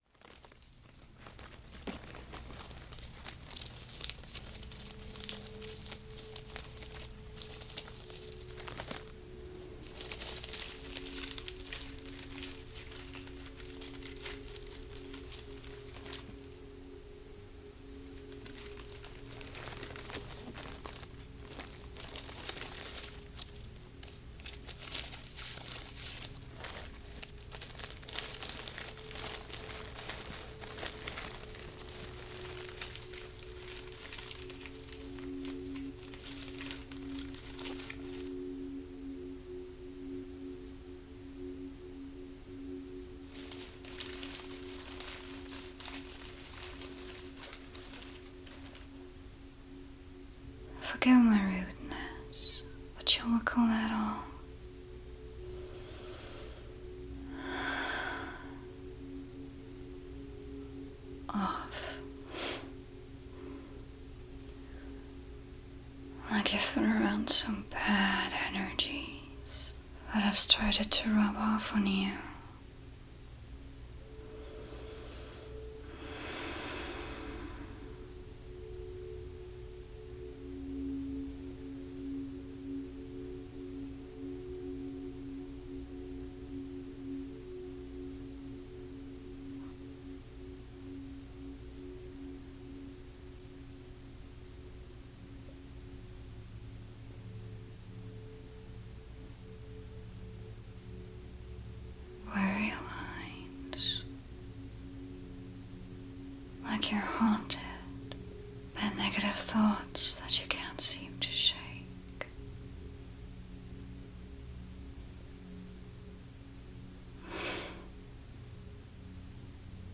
Your complementary mp3 download of my latest crystal healing video for many many nights of relaxing sleep!
[ASMR] crystal healing - clearing subliminal venom.mp3